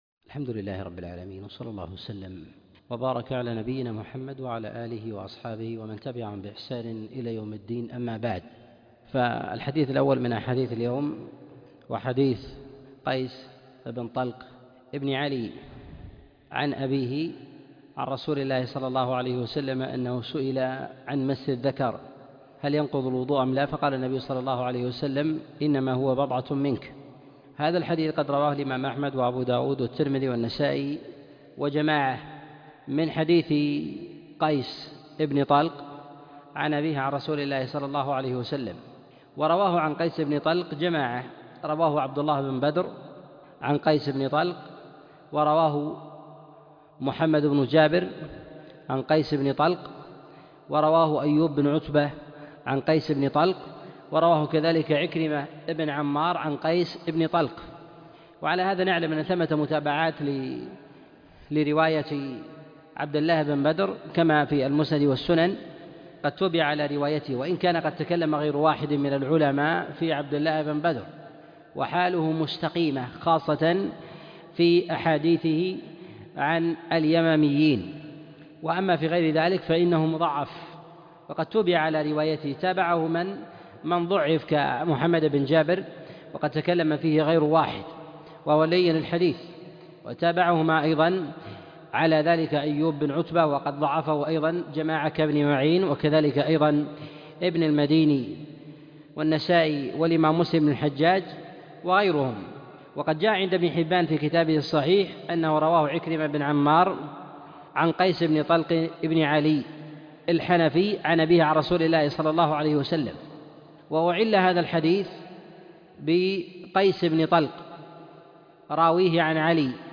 الأحاديث المعلة في الطهارة الدرس 12